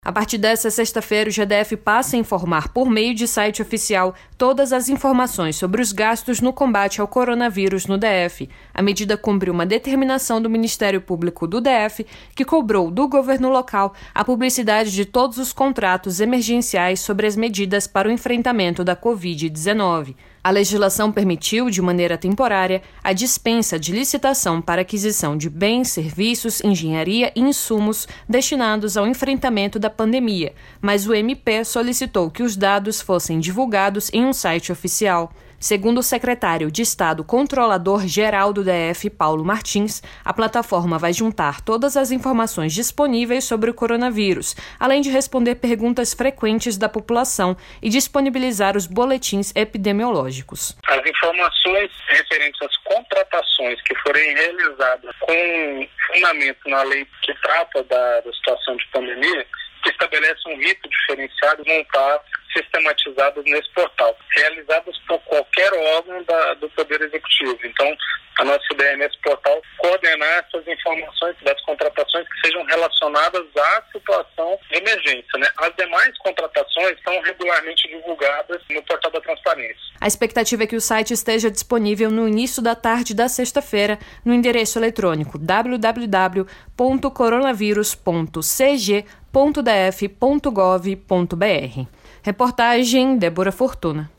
Entrevista do Controlador-geral Paulo Martins para a CBN